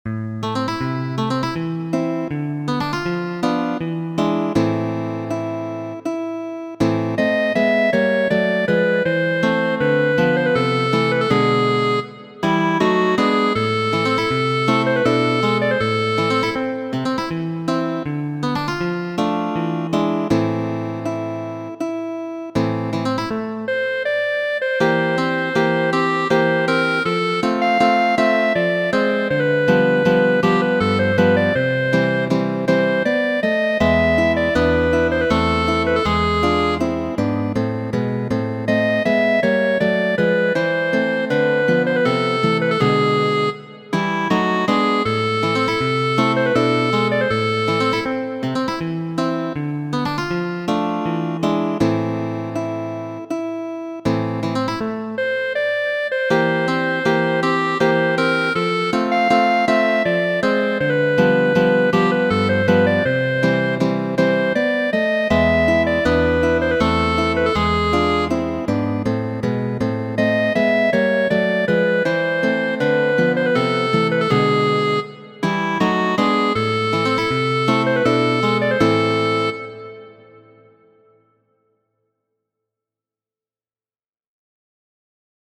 Ĉesu turmenti min! estas sekvidiljo verkita de Fernando Sor.